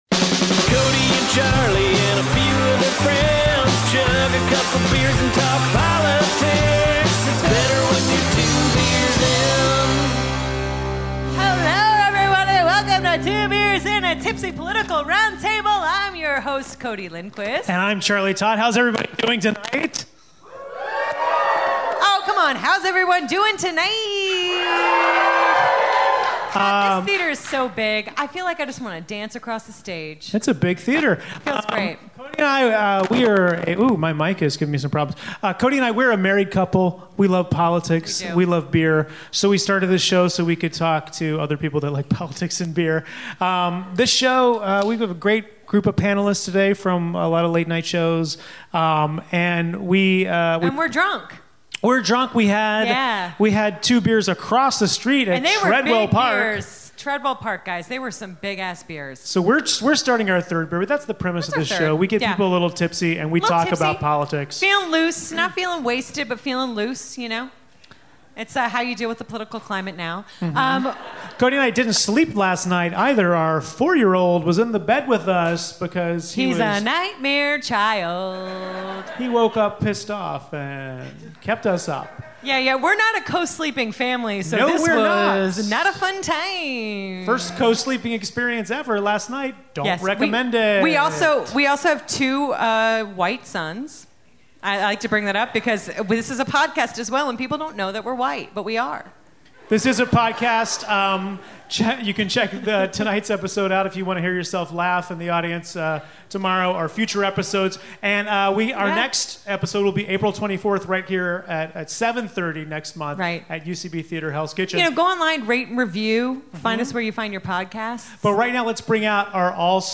at UCB Theatre Hell's Kitchen.